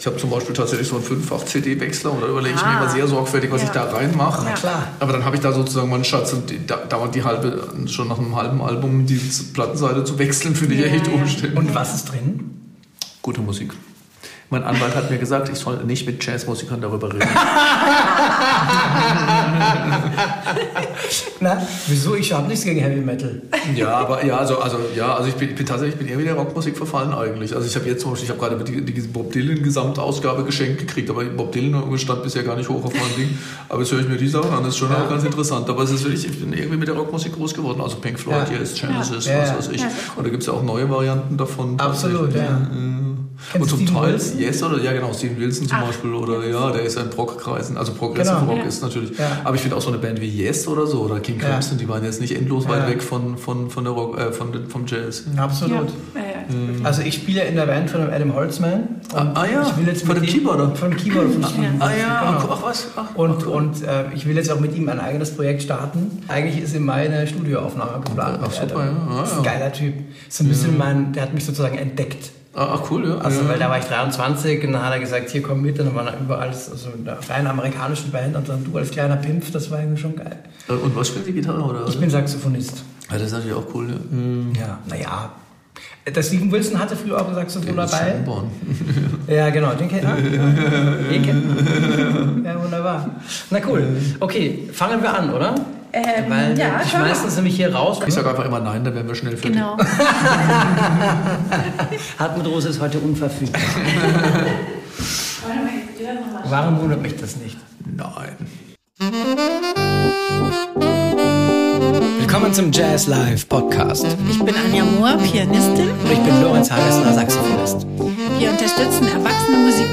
Dieses Interview ist für alle, die nicht nur Jazz spielen wollen – sondern Jazz als eine Form von innerer Freiheit entdecken möchten.